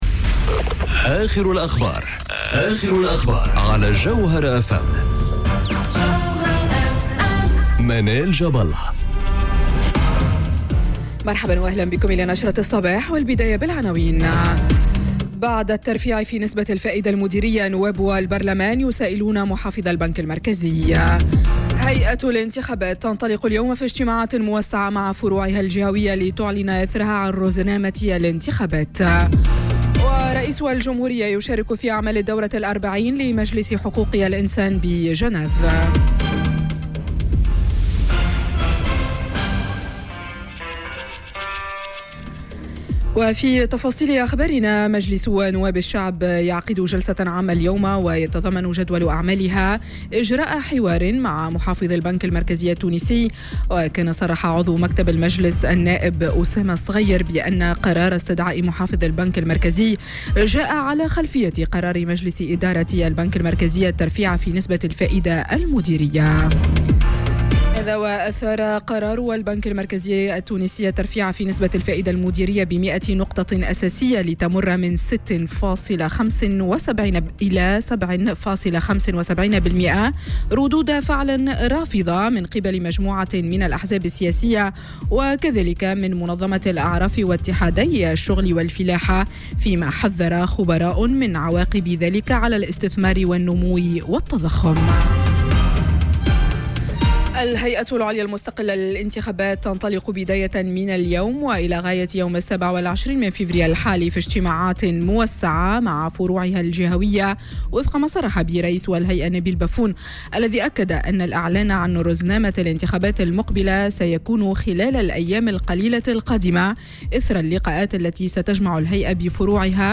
Journal Info 07h00 du lundi 25 Février 2019